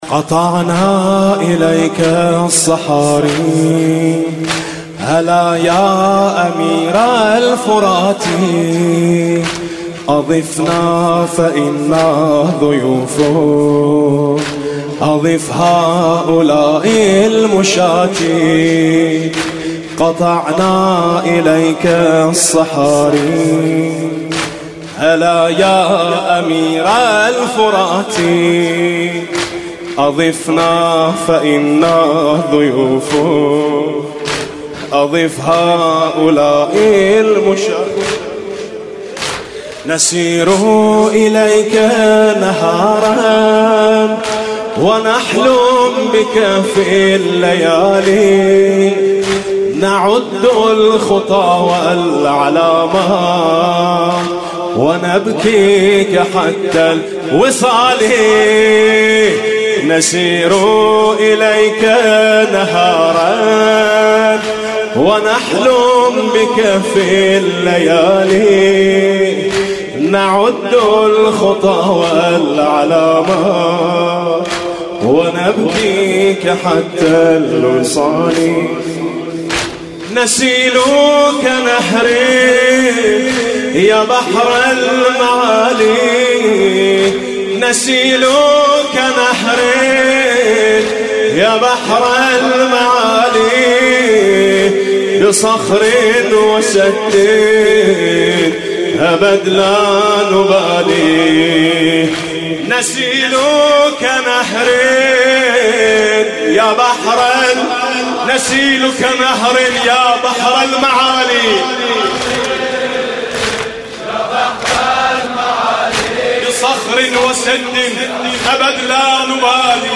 لطميات محرم